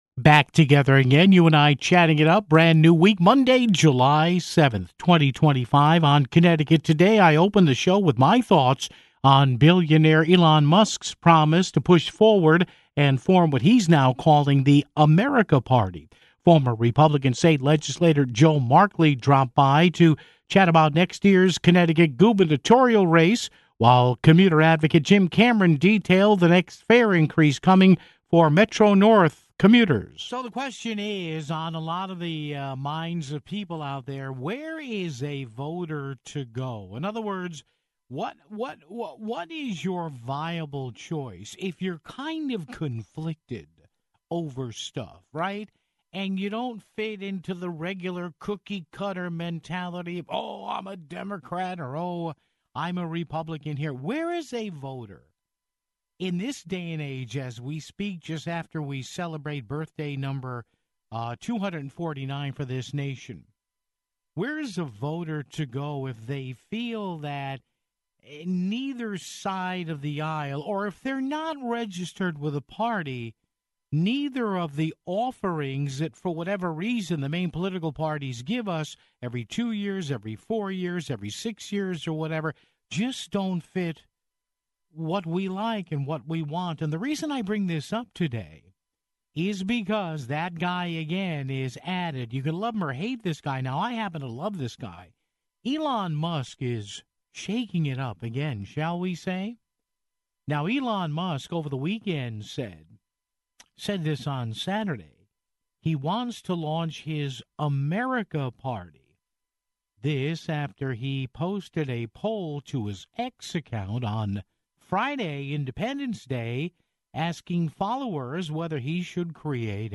Former GOP state legislator Joe Markley dropped by to chat about next year's Connecticut gubernatorial race (14:57)...